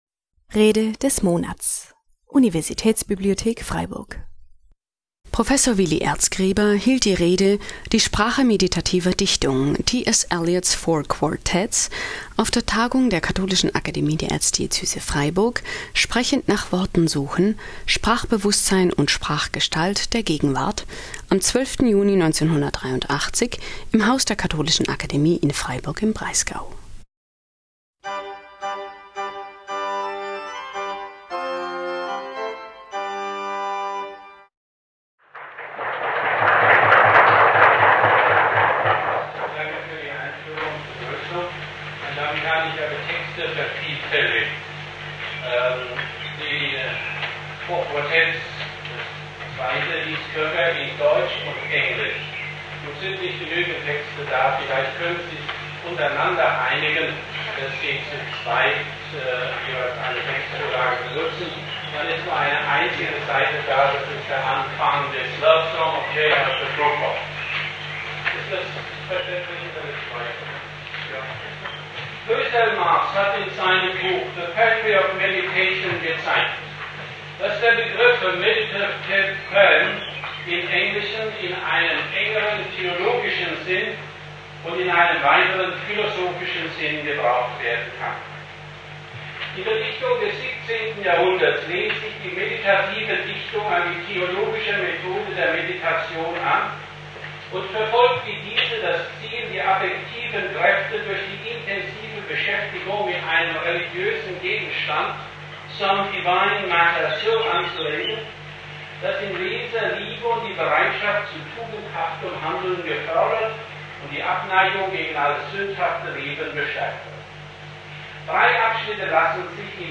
Die Sprache meditativer Dichtung - T. S. Eliots "Four Quartets" (1983) - Rede des Monats - Religion und Theologie - Religion und Theologie - Kategorien - Videoportal Universität Freiburg